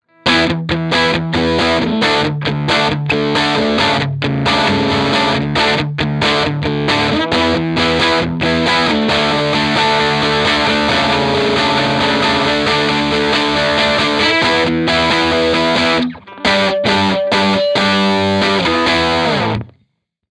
TC-15 DIRTY
Tone Tubby Alnico
The TC-15 tone was the Ch.1 EF86, "Lo" input, "munch".
TT alnico is sounding beautifull here.
TC15_DIRTY_ToneTubbyAlnico.mp3